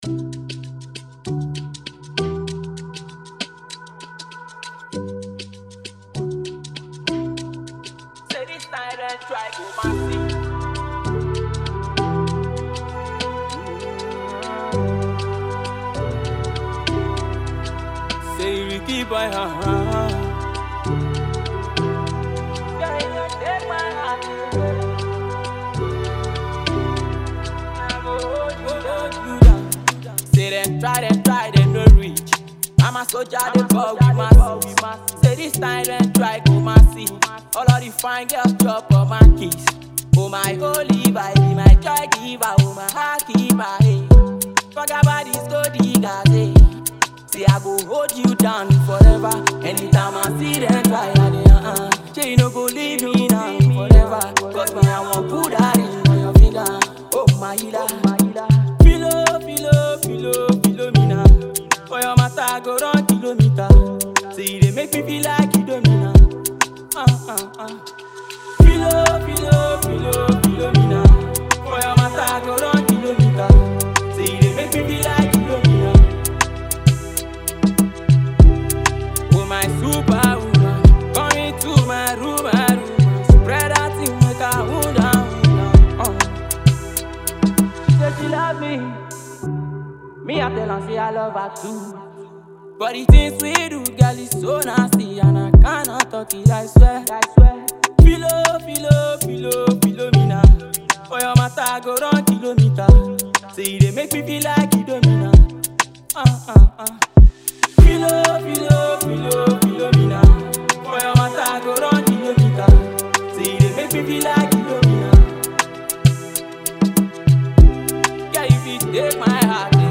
smooth vocals and catchy hooks